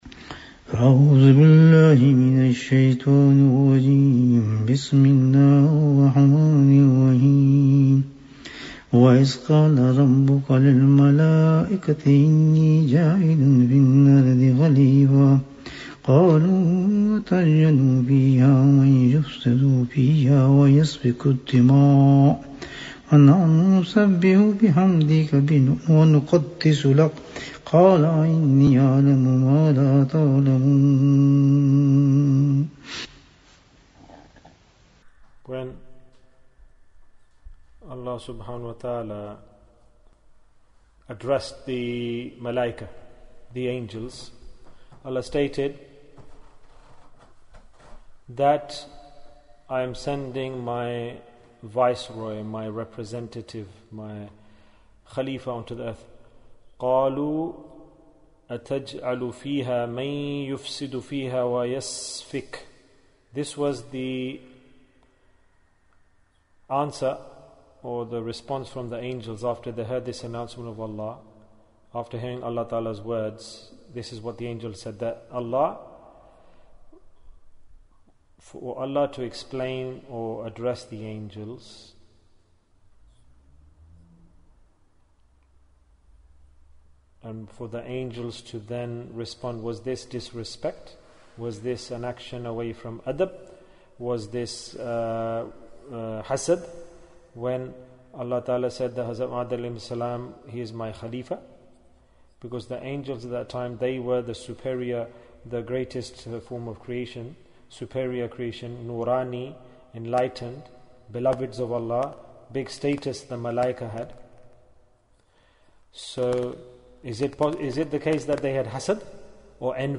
The Dialogue of Angels - Dars 29 Bayan, 46 minutes11th July, 2020